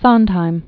(sŏndhīm), Stephen Joshua Born 1930.